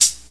Closed Hats
JayDeeHiHat3.wav